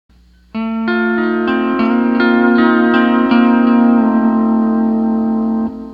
C6th with "DCA" Knee Lever Mechanics and Tab
Tab DCA5 - 7th Suspend 4 Sound Tab